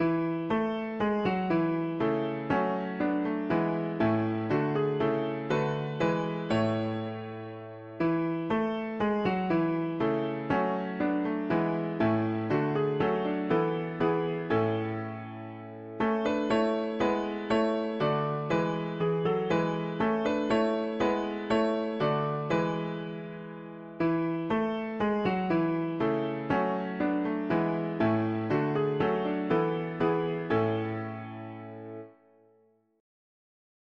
1868 Key: A major Meter: CMD Date Added